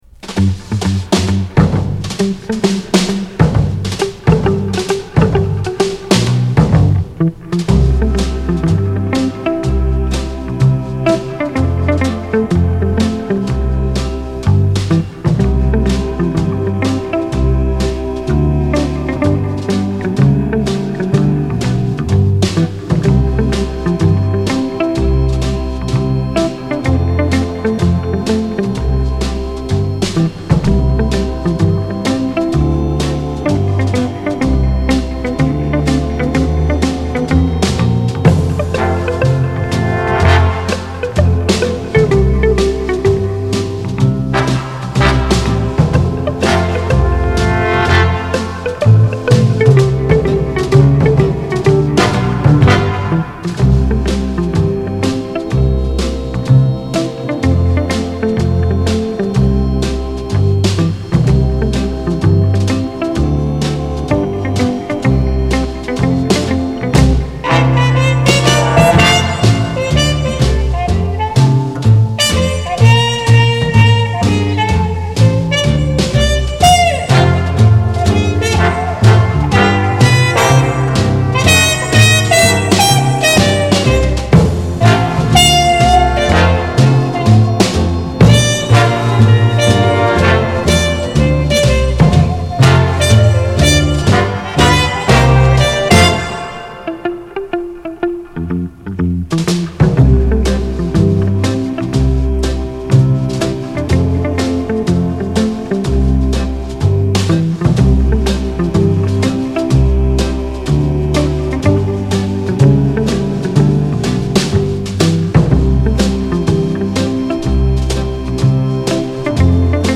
Genre: Jazz
Style: Easy Listening